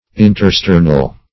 Search Result for " intersternal" : The Collaborative International Dictionary of English v.0.48: Intersternal \In`ter*ster"nal\, a. (Zool.) Between the sternal; -- said of certain membranes or parts of insects and crustaceans.